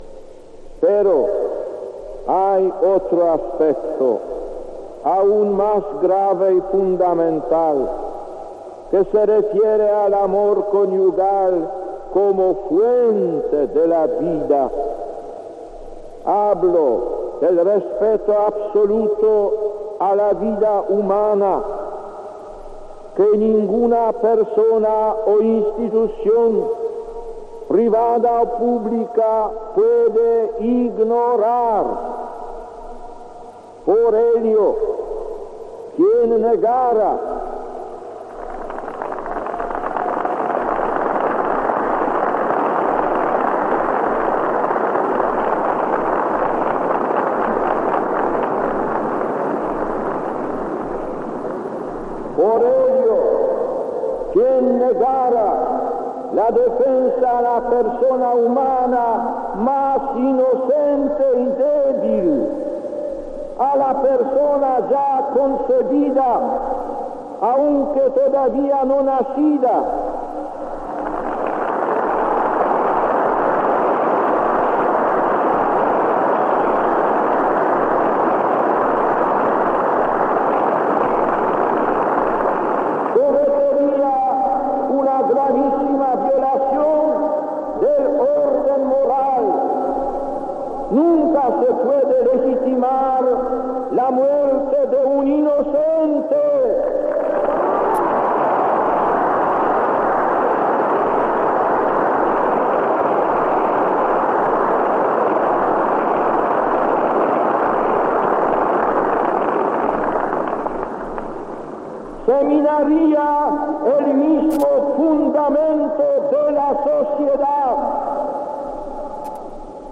Gracias a los documentos sonoros de la Cadena COPE hemos podido volver a escuchar la homilía de ese día y resulta sorprendente lo actual que pueden ser las palabras de Karol Wojtyla en una Plaza de Lima abarrotada de gente.